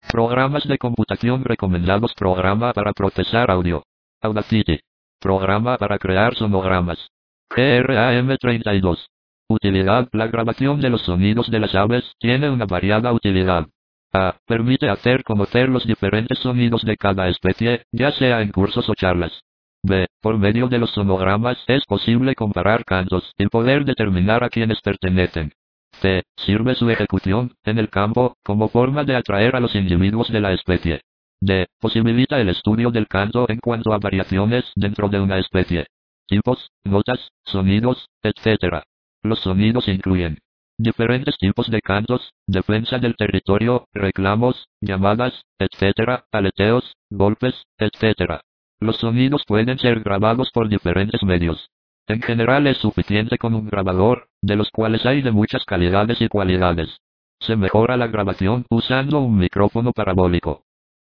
Escuchar el canto de algunas aves.